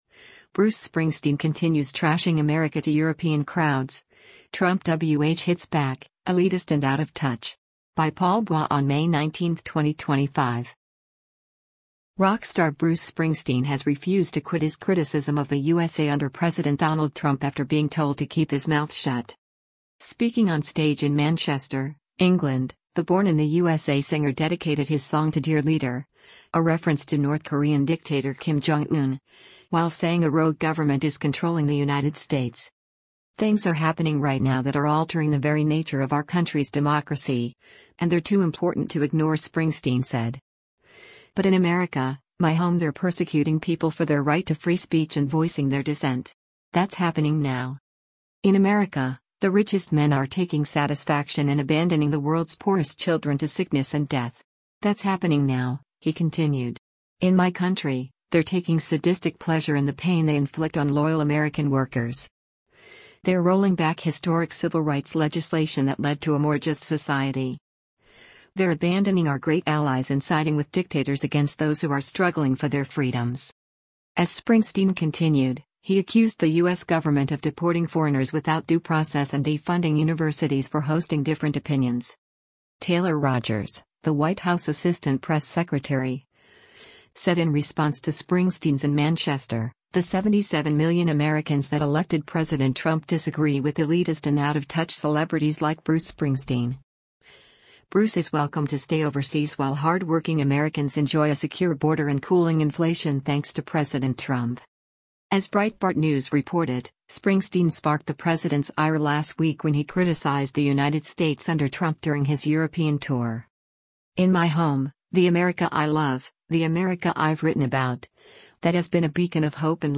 Speaking on stage in Manchester, England, the “Born in the U.S.A.” singer dedicated his song to “Dear Leader” – a reference to North Korean Dictator Kim Jong Un – while saying a “rogue” government is controlling the United States.